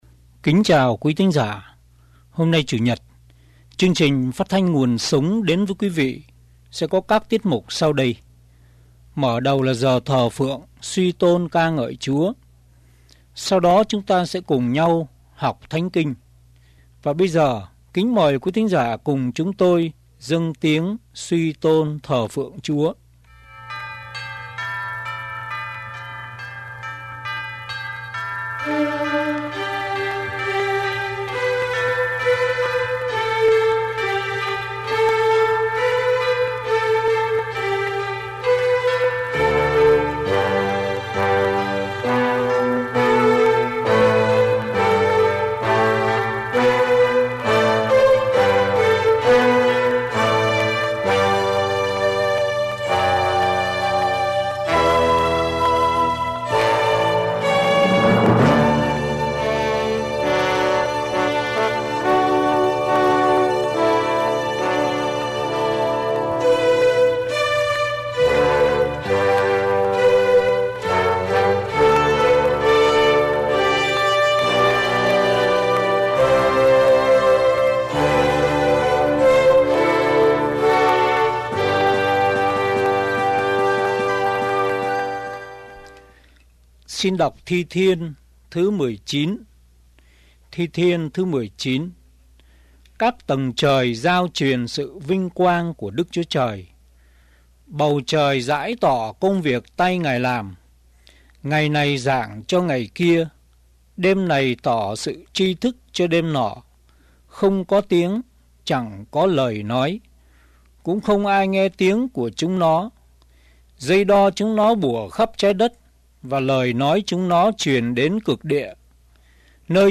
Thờ Phượng Giảng Luận